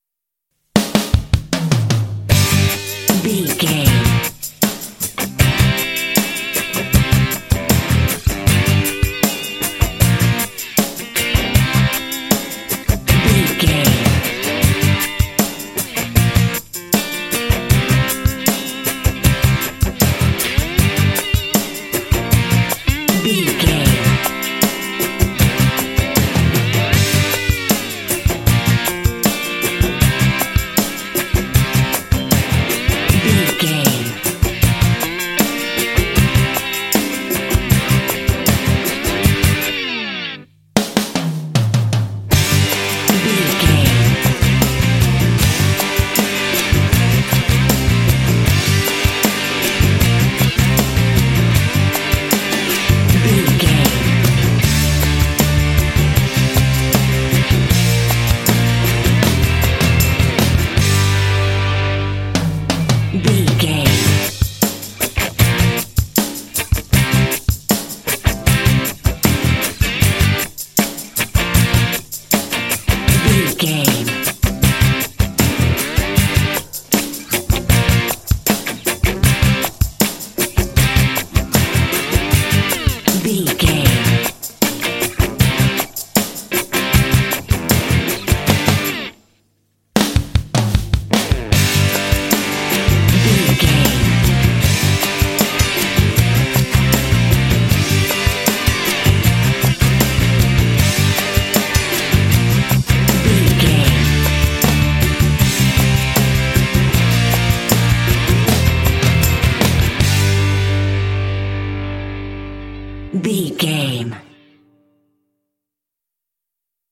Ionian/Major
driving
bouncy
happy
groovy
bright
motivational
electric guitar
drums
bass guitar
rock
alternative rock